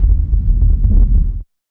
80 RUMBLE -R.wav